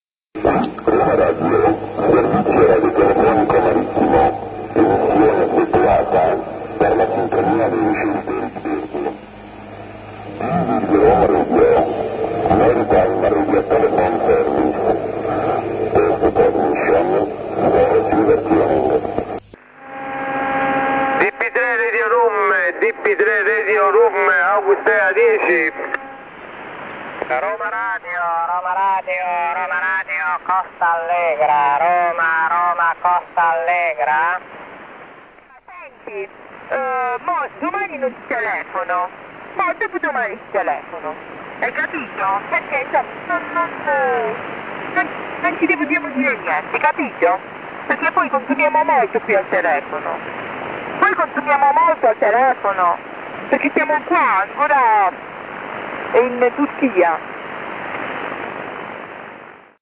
Eccolo… questo è forte ...si sente molto bene...